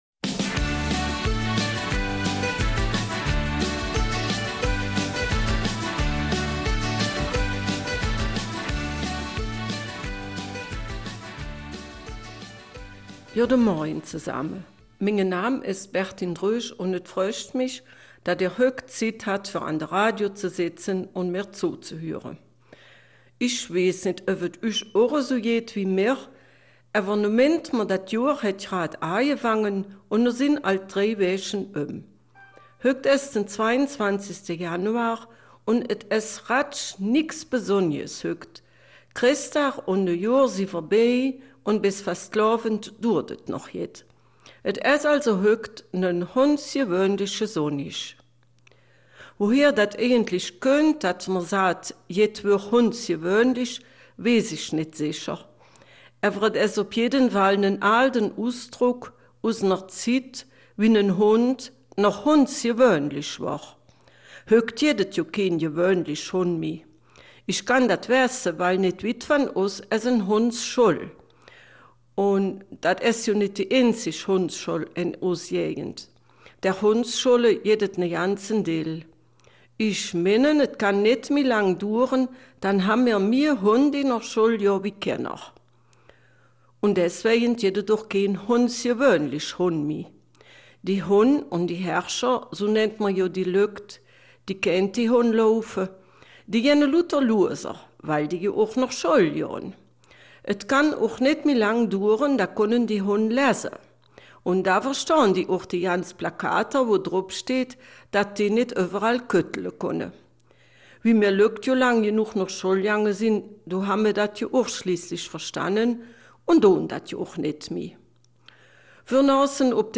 Eifeler Mundart - 22. Januar